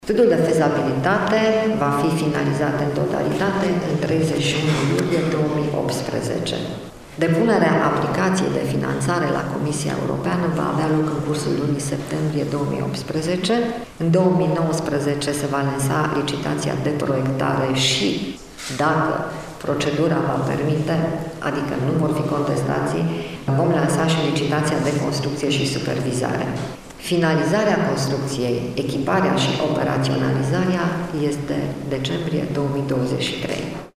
Valoarea investiţiei a fost anunţată astăzi, la Iaşi, de ministrul Sănătăţii Sorina Pintea în cadrul dialogurilor cu reprezentanţii Uniunii Europene.